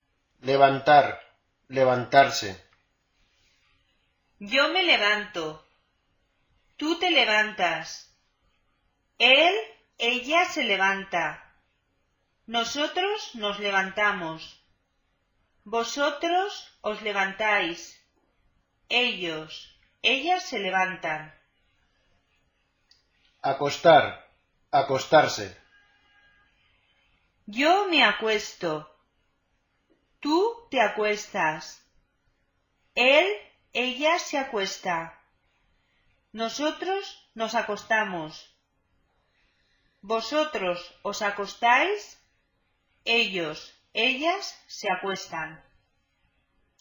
Escucha la pronunciación de los verbos anteriores.